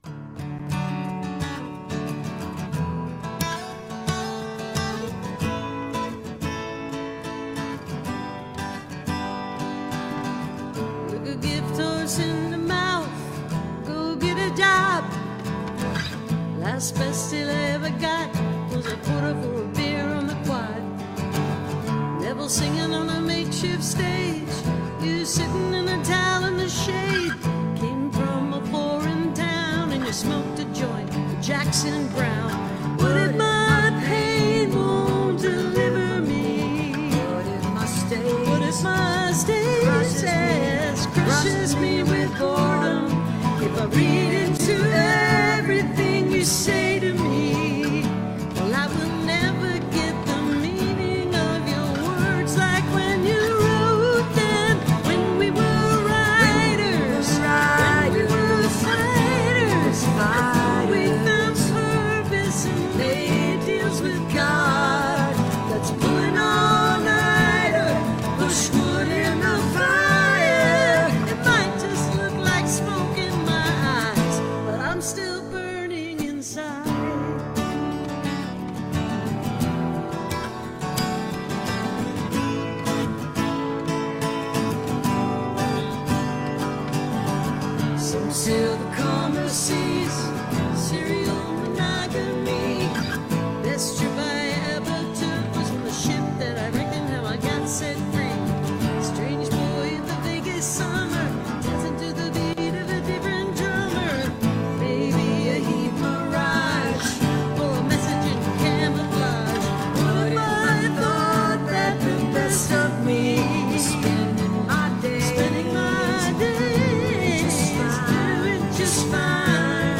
(captured from the vimeo livestream)